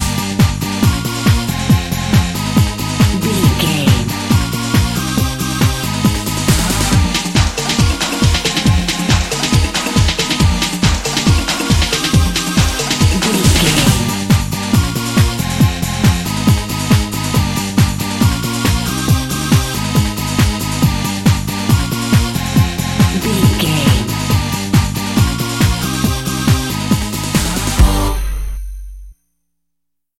Aeolian/Minor
Fast
drum machine
synthesiser
electric piano
Eurodance